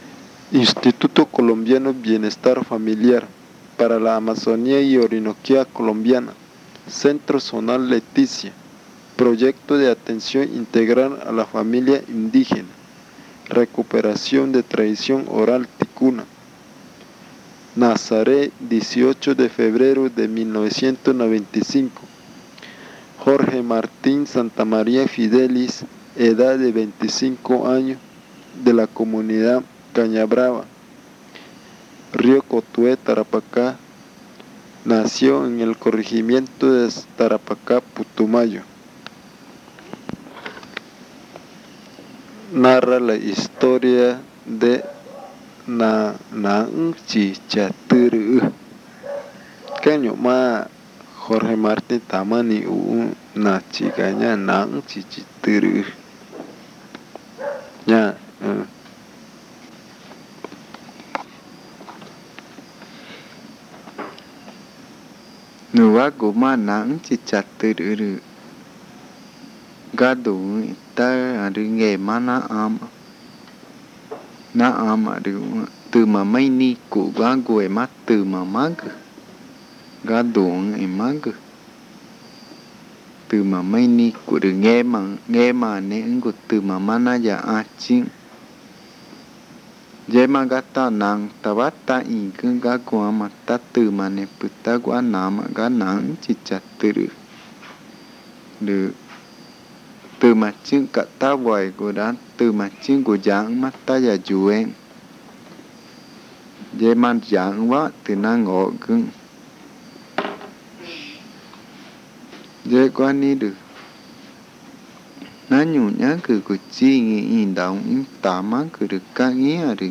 Nachiga 5. Narraciones magütá varias
El audio incluye los lados A y B del casete.